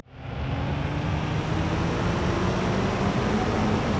Engine 8 Start.wav